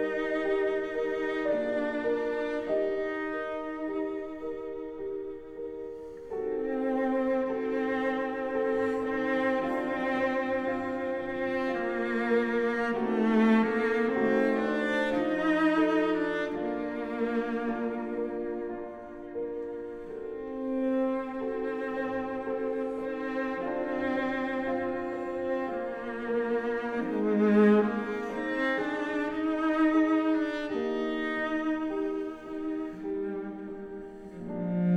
# Классика